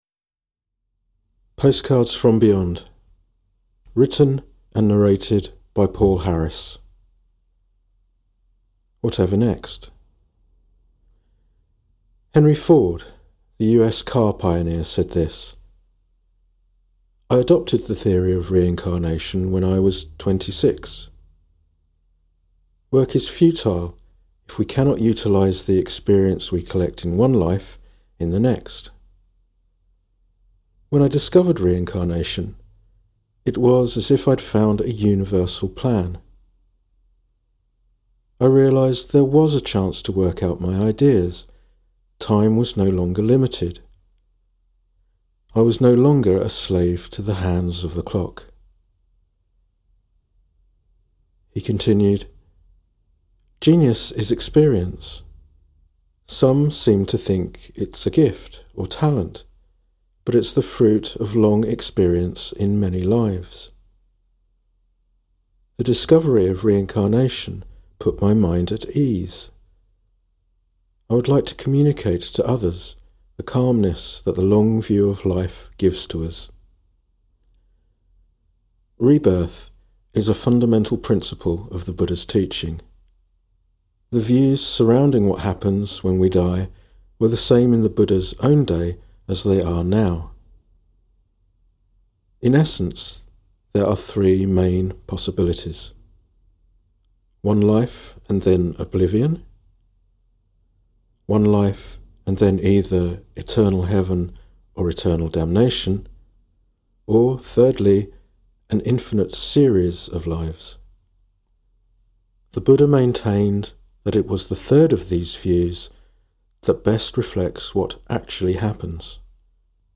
Audio recording of the book